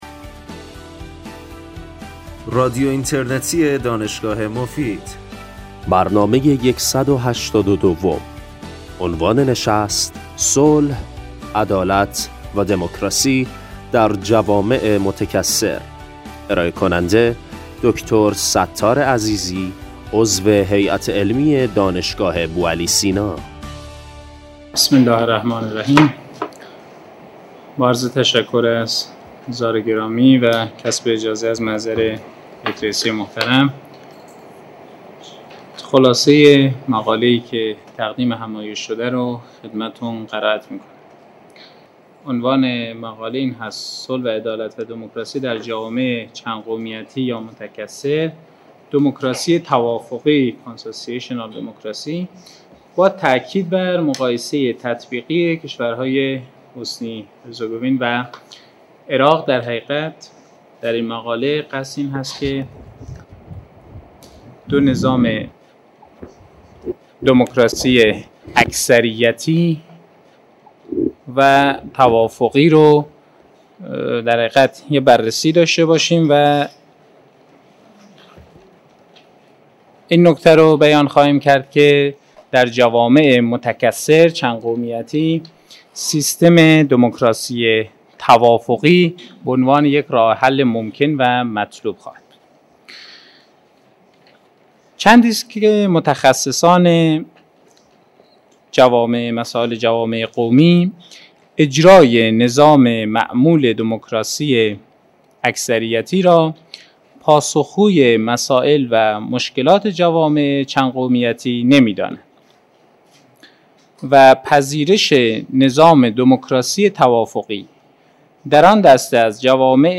ایشان در نهایت سیستم مبتنی بر دموکراسی توافقی را برای جوامع متکثر، مطلوب قلمداد می‌کنند. بخش پایانی برنامه به پرسش و پاسخ اختصاص دارد.